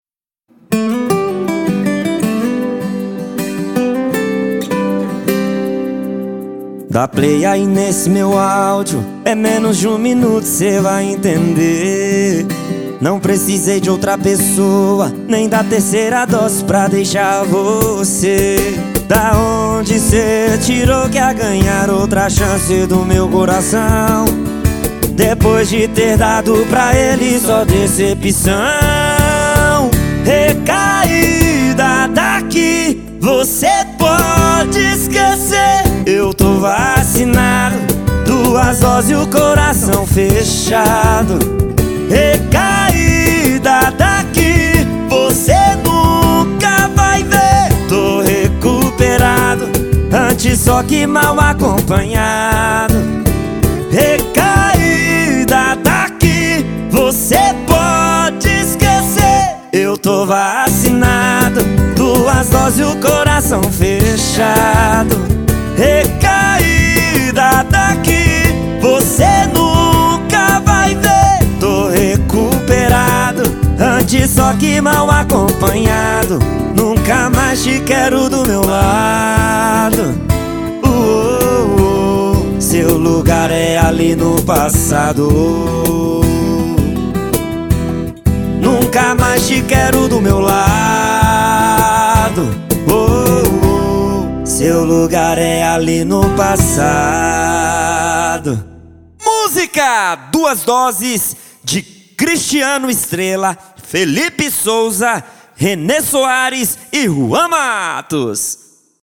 Guia caseira - Botequeira